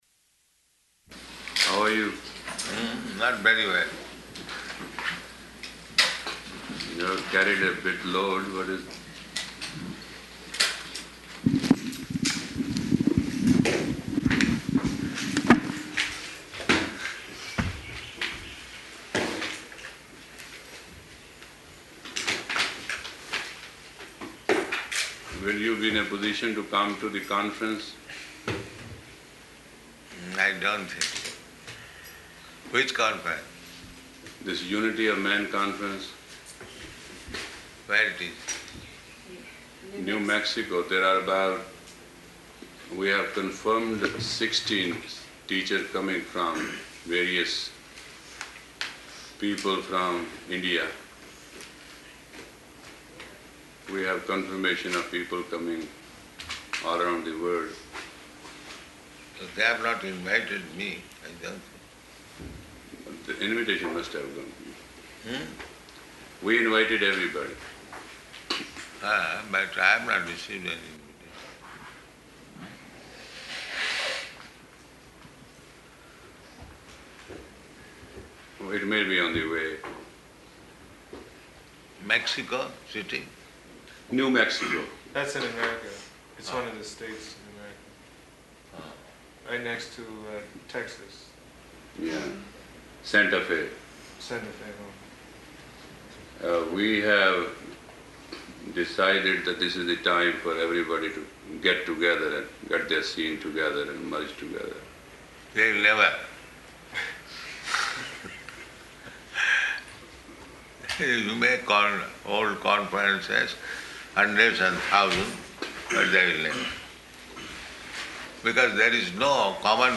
Room Conversation with Yogi Bhajan
Room Conversation with Yogi Bhajan --:-- --:-- Type: Conversation Dated: June 7th 1975 Location: Honolulu Audio file: 750607R1.HON.mp3 Yogi Bhajan: How are you?